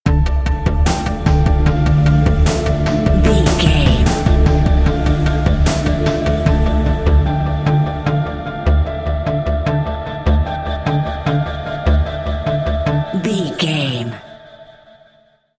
Thriller
Aeolian/Minor
Slow
drum machine
synthesiser
electric piano
ominous
dark
suspense
haunting
creepy